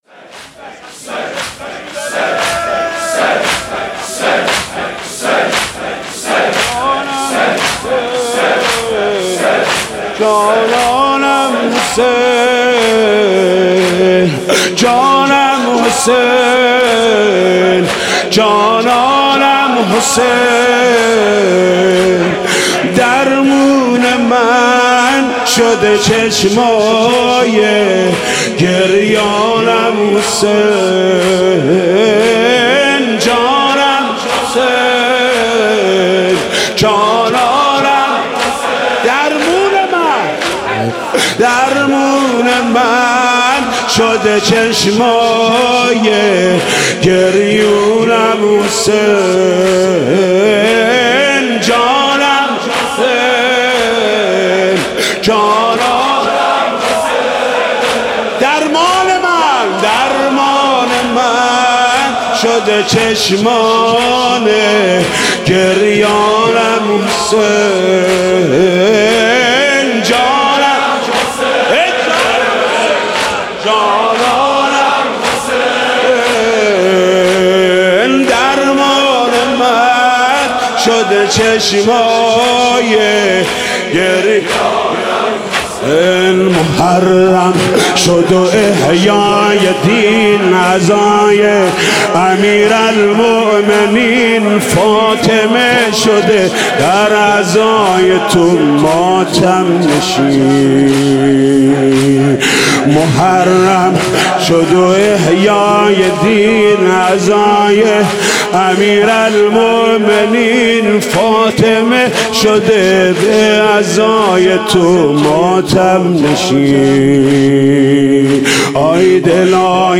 شب اول محرم - به نام نامی حضرت مسلم(ع)
محرم95 | زمینه | جانم حسین جانانم حسین
حاج محمود کریمی
محمود کریمی مداحی شب اول زمینه شب اول زمینه